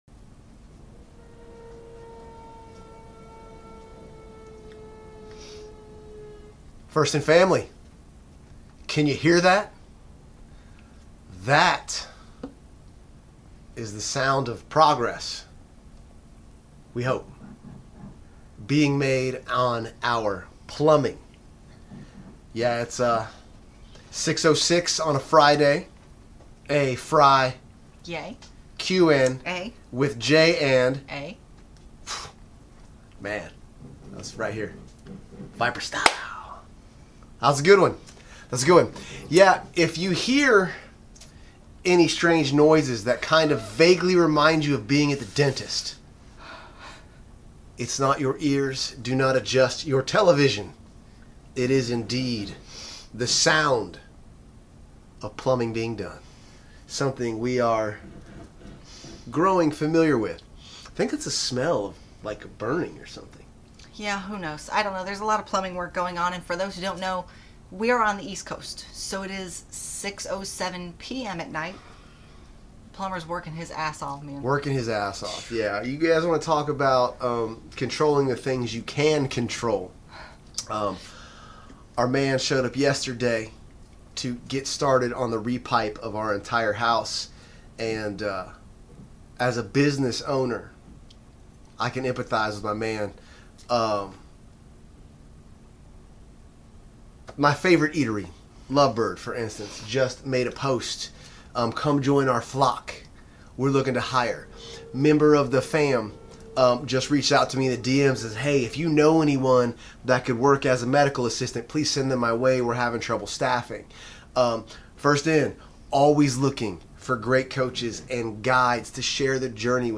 Kicked it off the with sounds of a re-pipe going on so you can tell what the focus of this one was going to be!? It was a questionless Q+A, but we did our best to keep it entertaining while sharing our experiences without water/indoor plumbing...